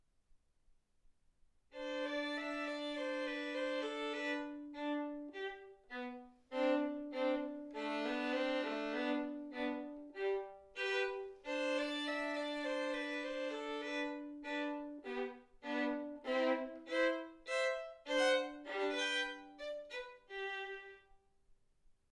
Hegedű etűdök Kategóriák Klasszikus zene Felvétel hossza 00:22 Felvétel dátuma 2025. december 8.